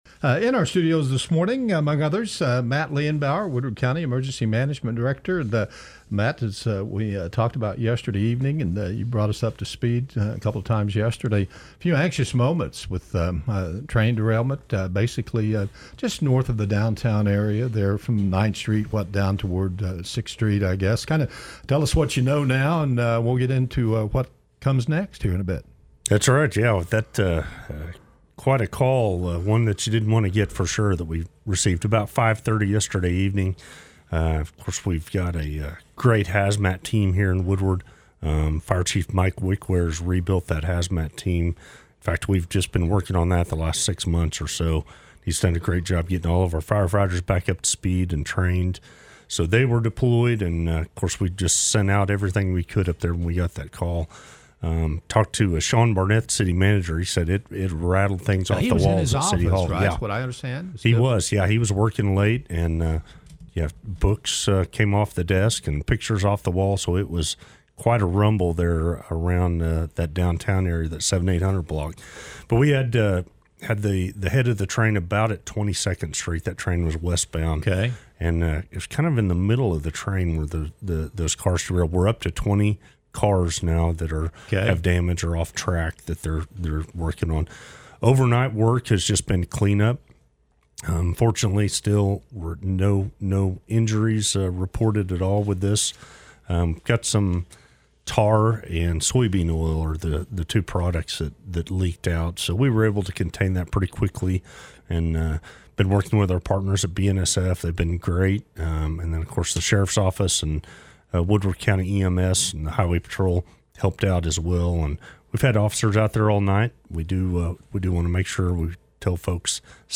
joined the K-101 Morning Show to update the situation downtown and explain the plans for cleanup.